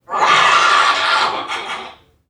NPC_Creatures_Vocalisations_Robothead [8].wav